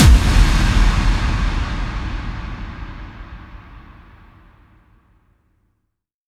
VEC3 FX Reverbkicks 27.wav